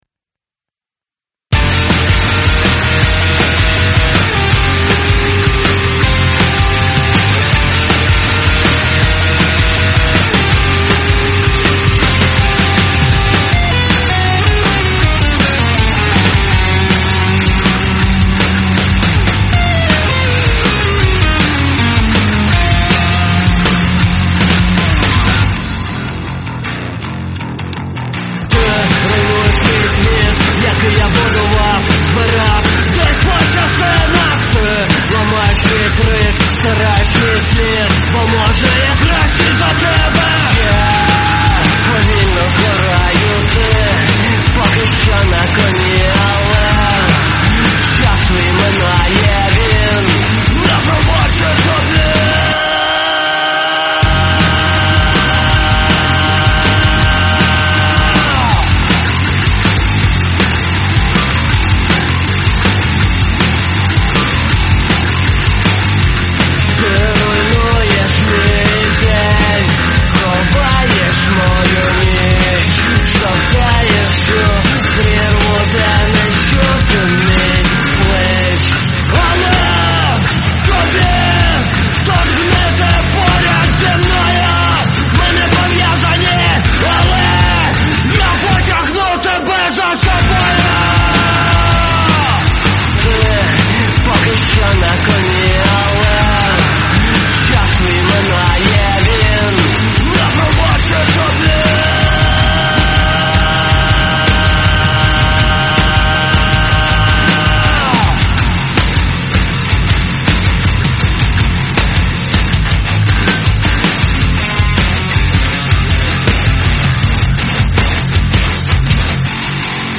Стиль: Гранж\Альтернатива